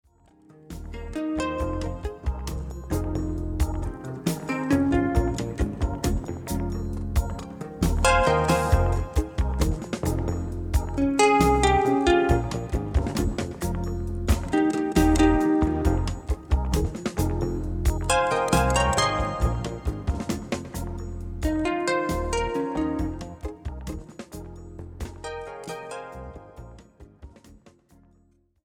Re-mastered, including bonus songs and bonus videos
electroacoustic pedal harp, gu-cheng & more...
weaver of the bata drum net
Recorded and mixed at the Sinus Studios, Bern, Switzerland